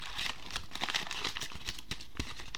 Various Paper Sound Effects
paper_sound_-_4.mp3